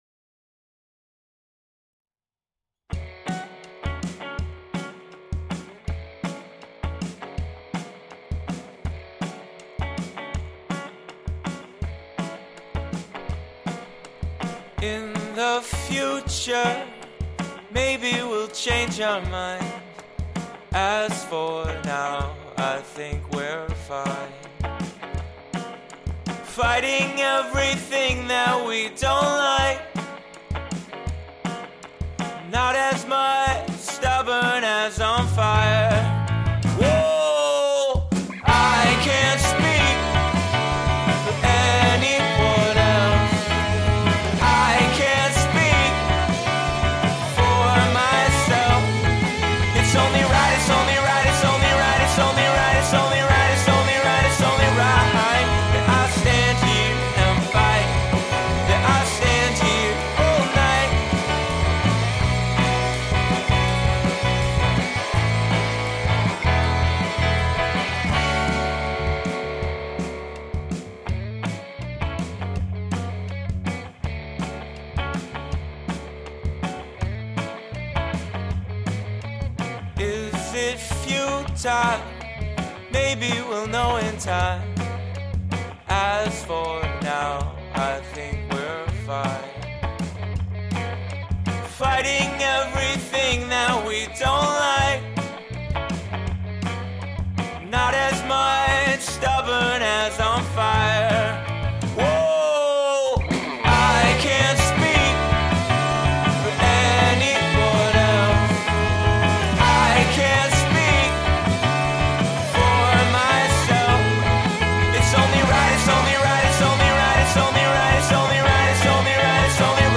I've been recording my band and was hoping I could get some helpful tips to get a better mix? It's probably really quiet, so you might have to turn up a lot to hear it, but I don't really get how to make it louder (aside from limiting) without drastically changing the EQ or having the mix clip.